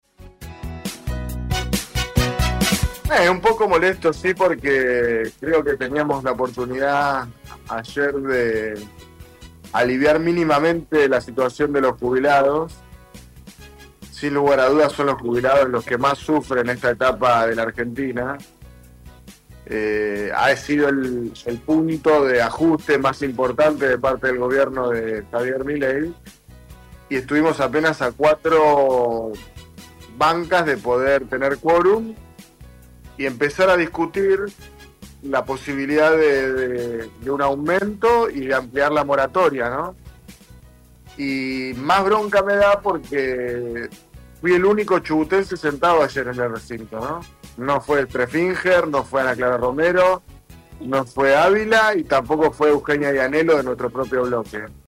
El diputado nacional José Glinski expresó su descontento en el aire de LA MAÑANA DE HOY al contar lo sucedido en la sesión de ayer, sesión clave para debatir moratoria previsional y actualización del bono para jubilados que cobran la mínima. La sesión reunió a 125 legisladores, quedando a sólo 4 de los 129 necesarios para lograr el quórum.